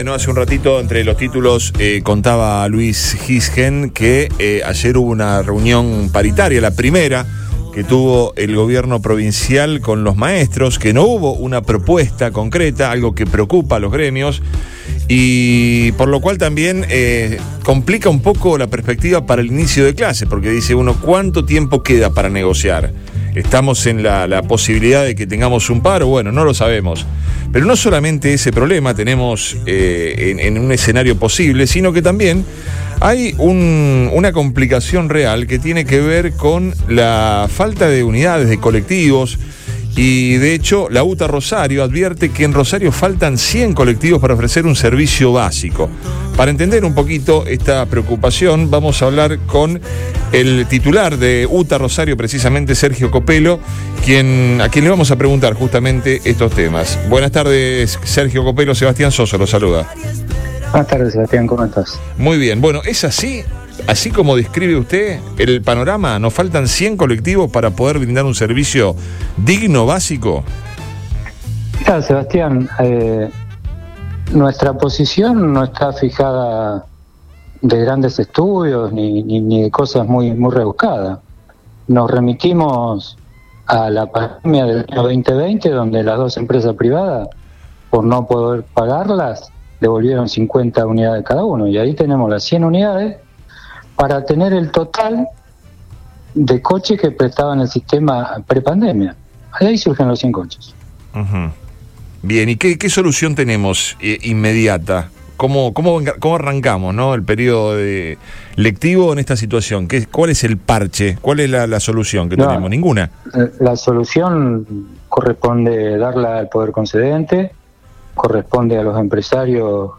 dialogó con Después de Todo por Radio Boing y brindó detalles de la situación.